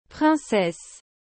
A pronúncia de princesse em francês é algo como “prrãssés”. O som nasal da primeira sílaba é um detalhe superimportante para soar como um falante nativo.
• O “pr” inicial deve ser suave, sem aquele “P” explosivo do português.
• O “in” tem um som nasal parecido com “mão”, mas um pouco mais fechado.